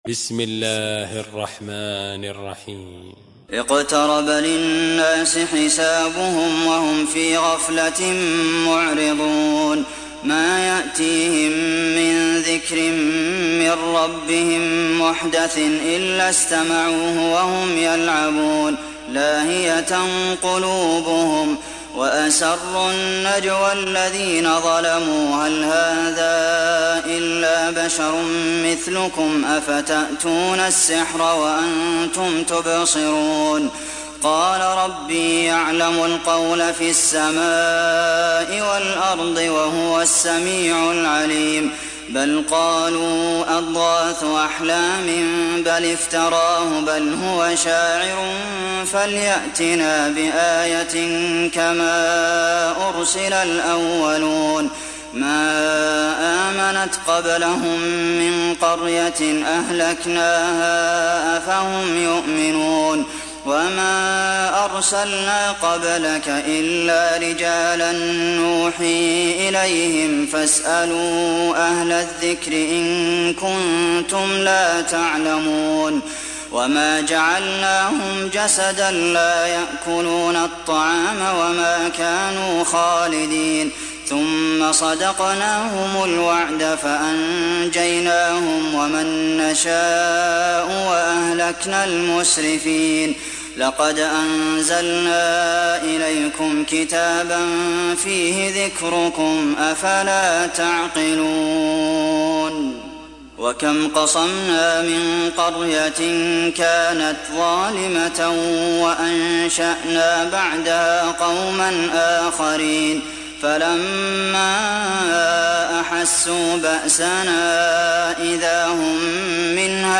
Enbiya Suresi mp3 İndir Abdulmohsen Al Qasim (Riwayat Hafs)